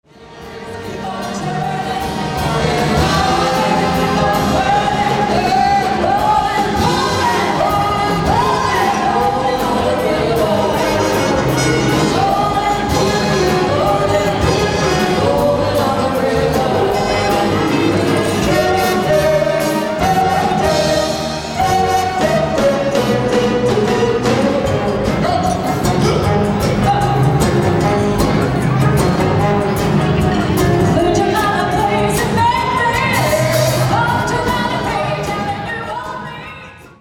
Vocalist
Live